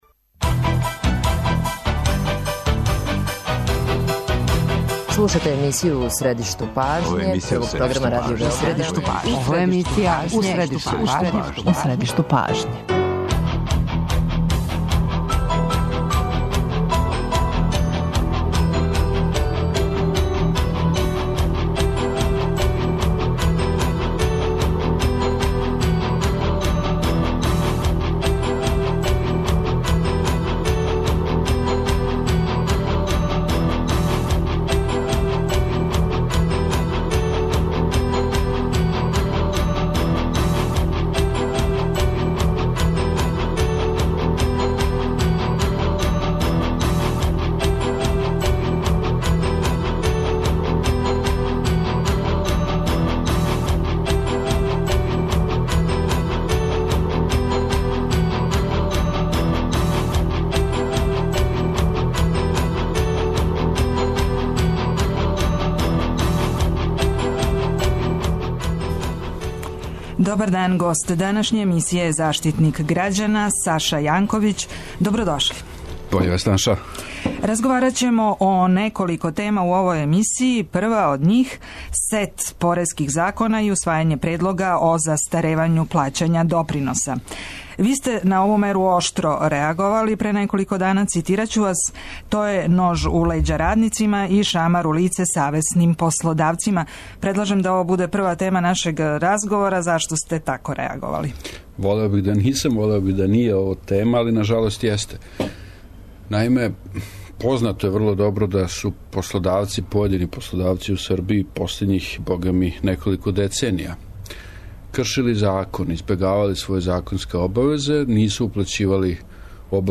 Како и чиме ће се допунити „рупе" у стажу - једно је од питања за госта емисије, заштитника грађана Сашу Јанковића.